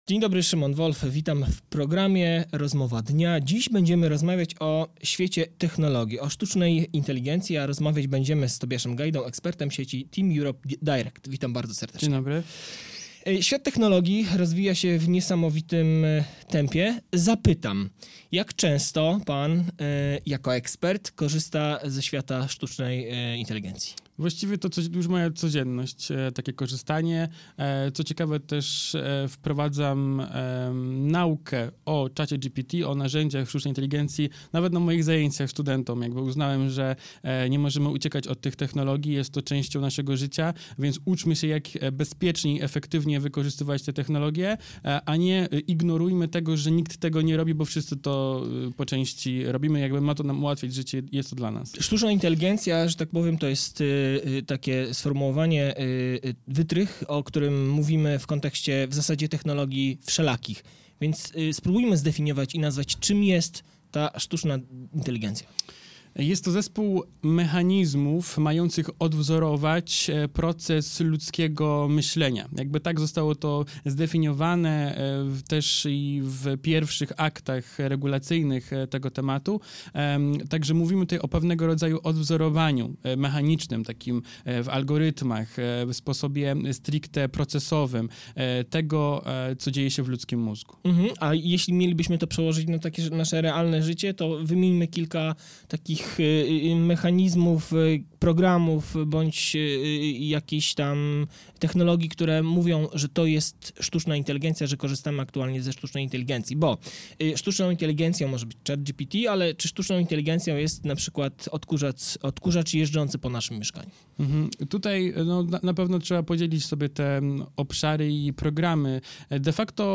Cała rozmowa do obejrzenia także na platformie YouTube ( TUTAJ ).